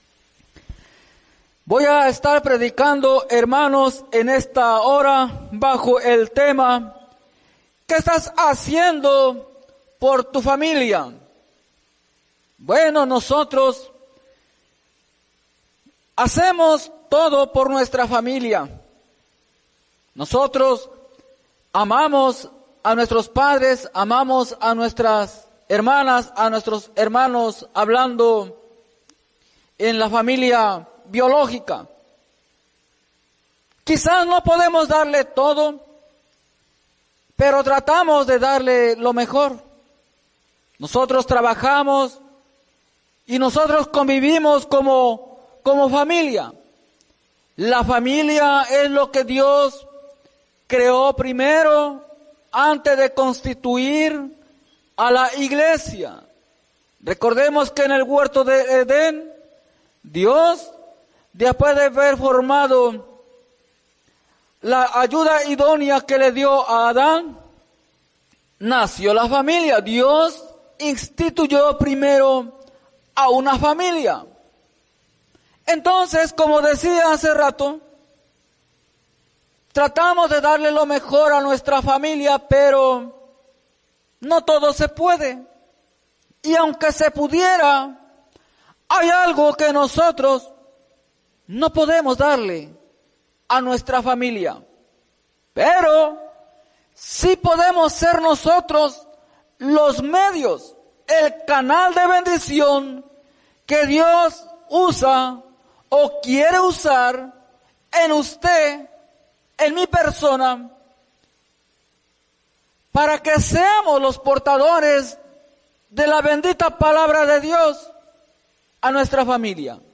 en la Iglesia Misión Evangélica en Norrsitown, PA